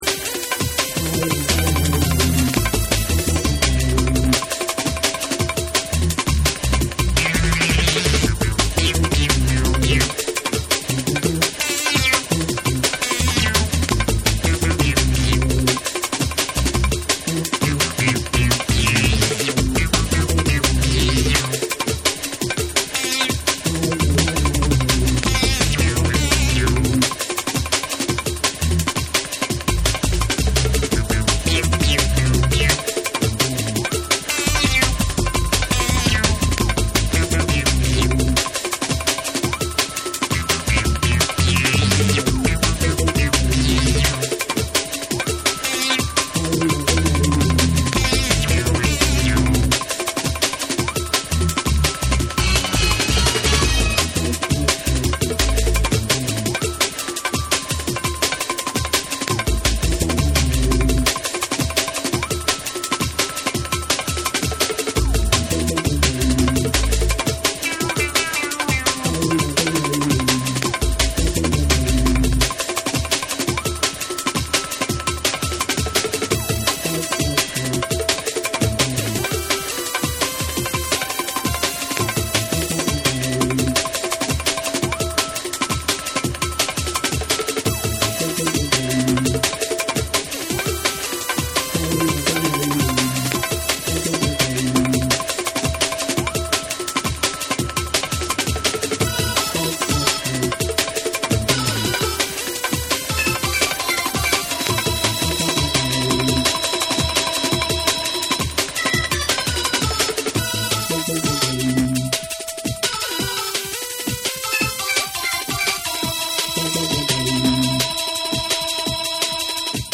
緻密かつリズミカルに刻まれるパーカッシヴなリズムに唸りまくるシンセが溶け込む1。